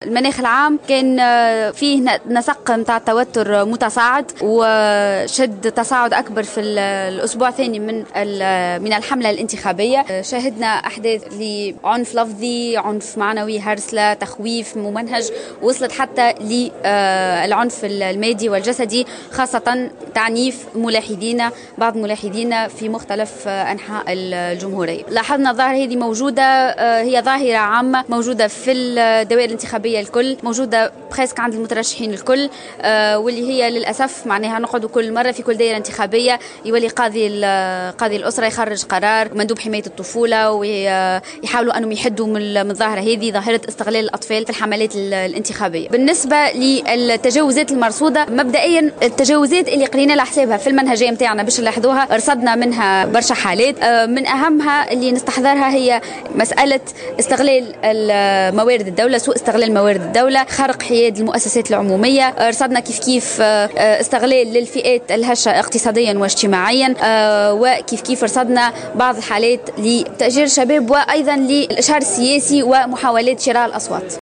قدمت منظمة أنا يقظ في ندوة صحفية حصيلة عملية ملاحظة الحملات الانتخابية الرئاسية...